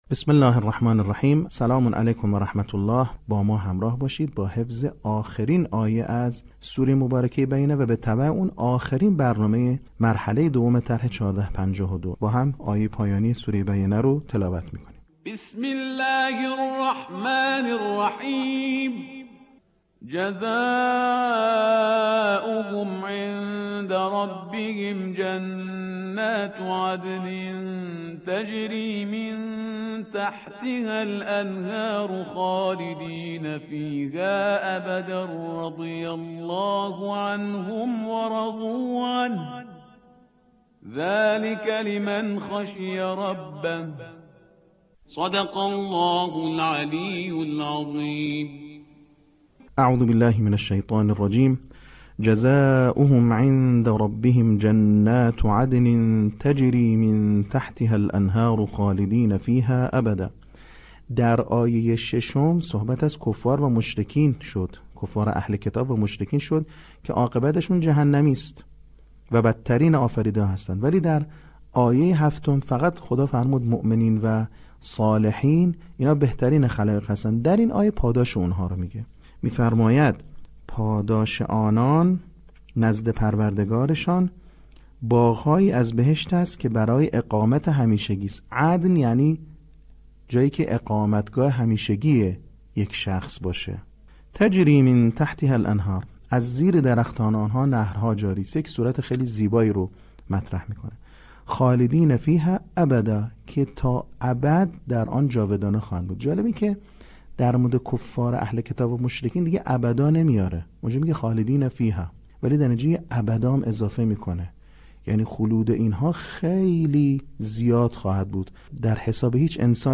صوت | بخش چهارم آموزش حفظ سوره بینه
به همین منظور مجموعه آموزشی شنیداری (صوتی) قرآنی را گردآوری و برای علاقه‌مندان بازنشر می‌کند.